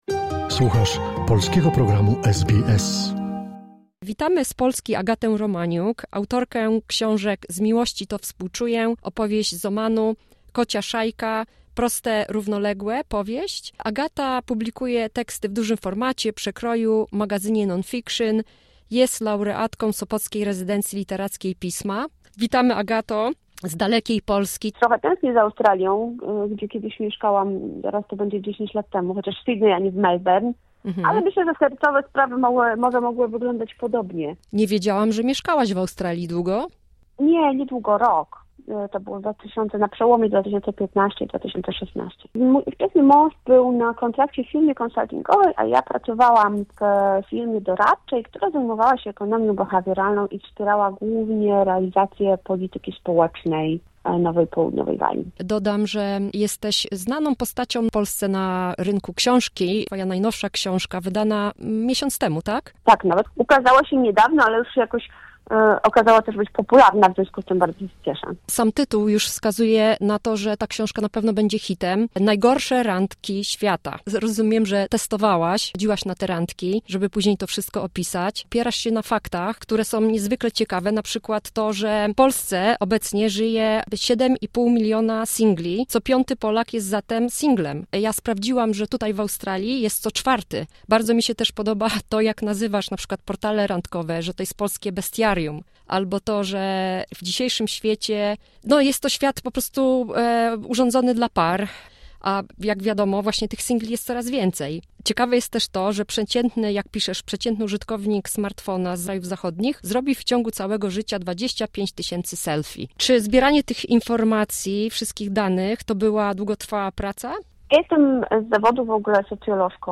Rozmowa z Polski